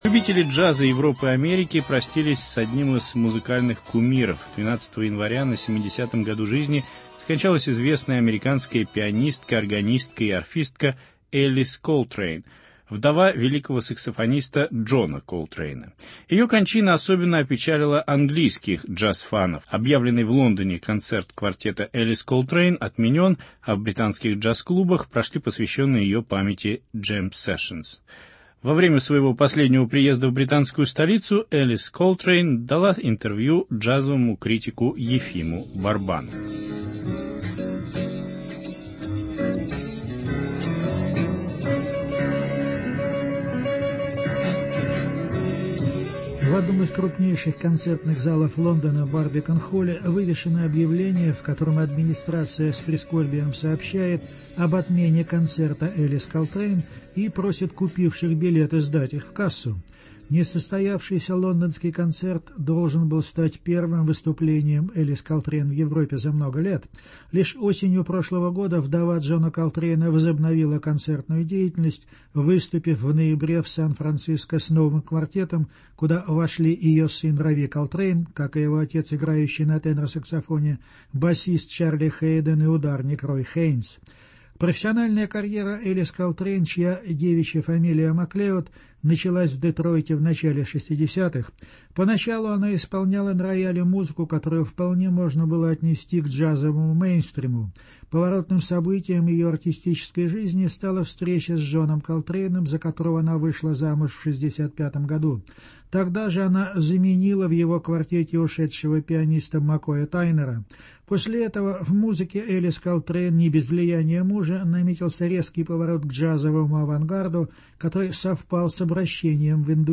Европейское интервью Элис Колтрейн (Памяти музыкантши).